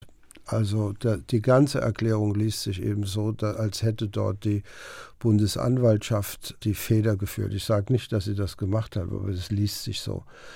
HR-Interview, Ausschnitt: